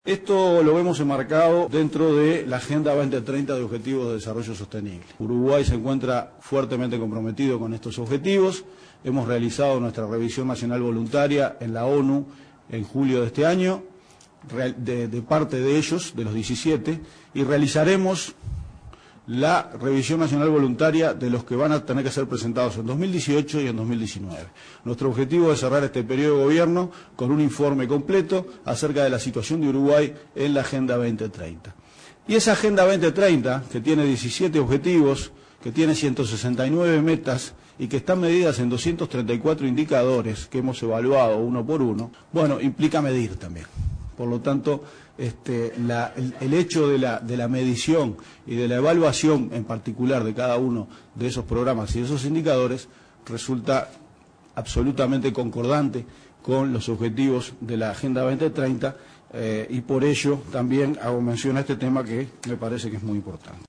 Uruguay se encuentra comprometido con la agenda 2030 de objetivos de desarrollo sostenible de ONU y realizará la revisión voluntaria de parte de los 17 objetivos en 2018 y 2019. Así lo adelantó el director de OPP, Alvaro García, en la presentación del Plan de Desarrollo de Capacidades de Monitoreo y Evaluación.